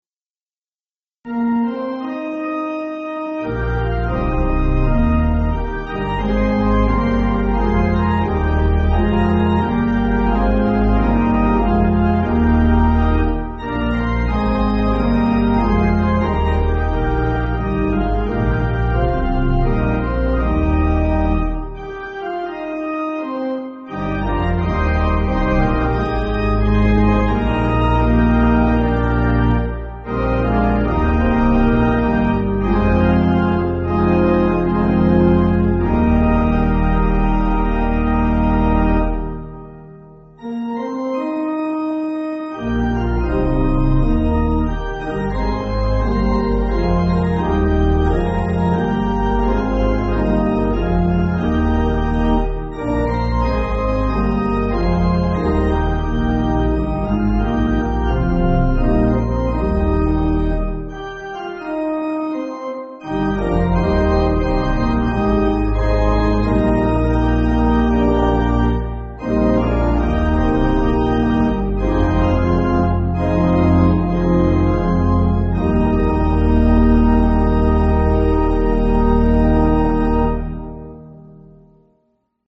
Organ
(CM)   2/Eb